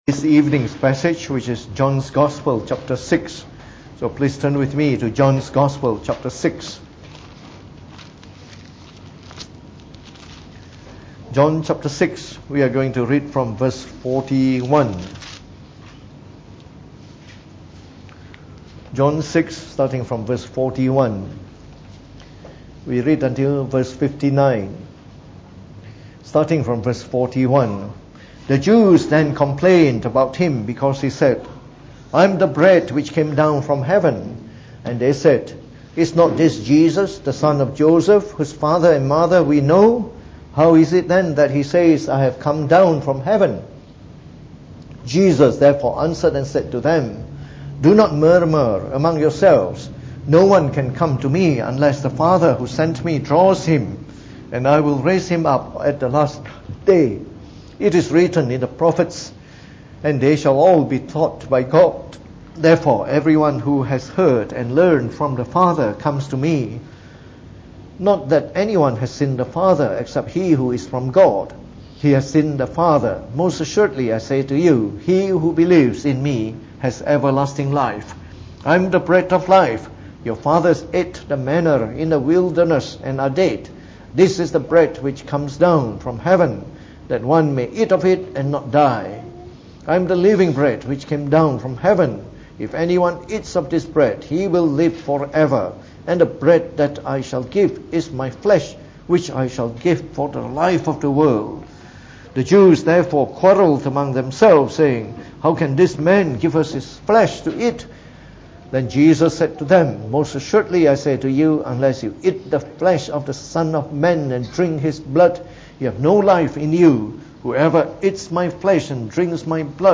Preached on the 28th October 2018.